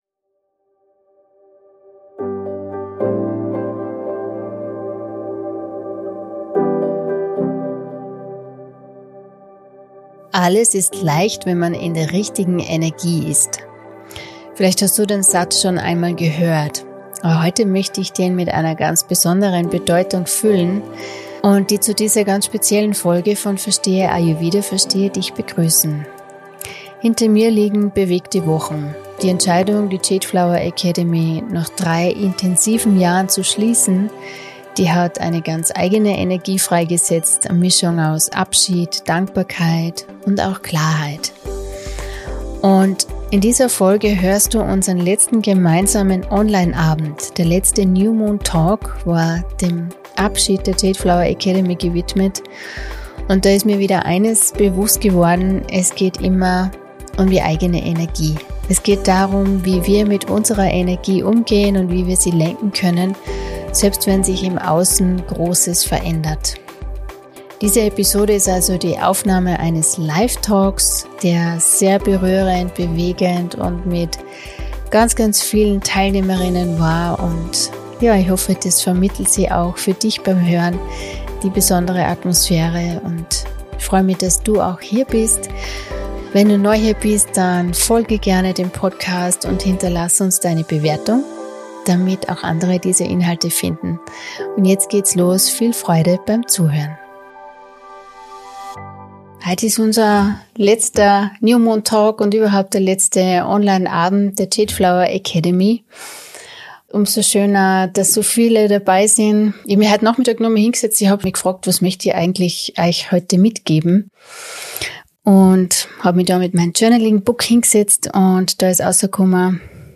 den letzten Onlineabend der JADEFLOWER Academy. Sie teilt darin essenzielle Erkenntnisse aus den letzten Jahren ihrer Aktivität.